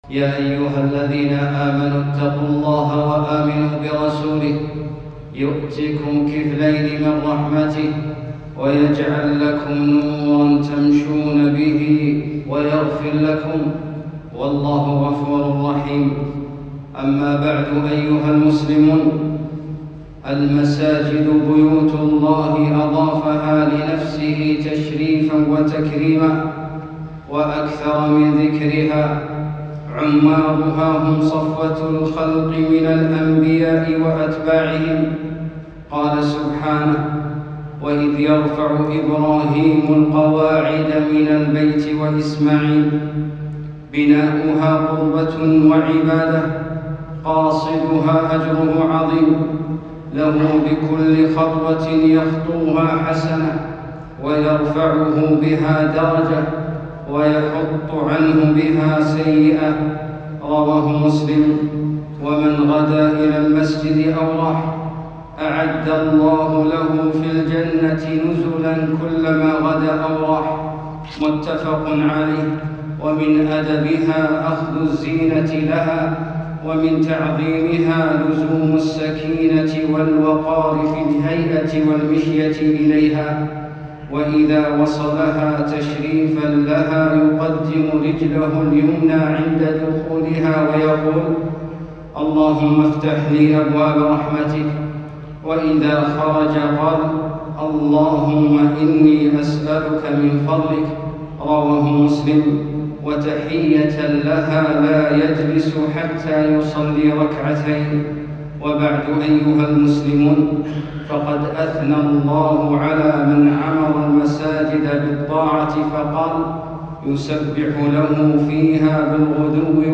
بيوت الله - خطبة